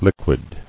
[liq·uid]